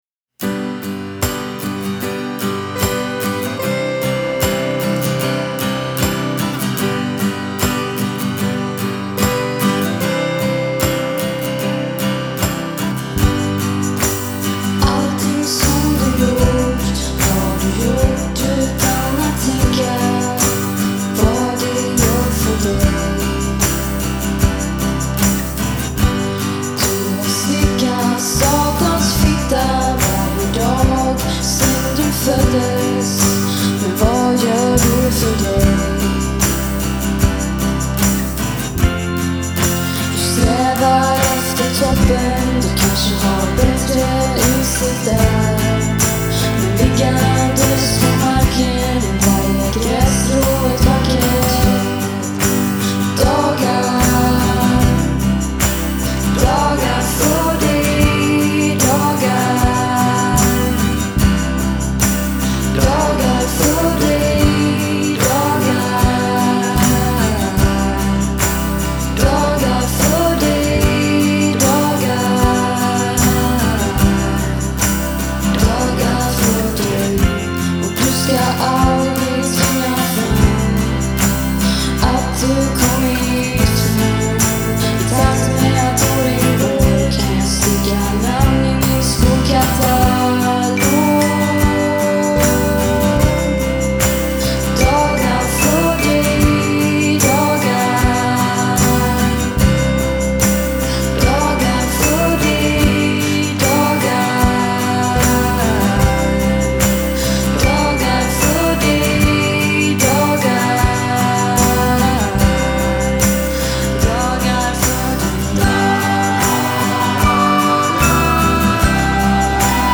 raffinato folk pop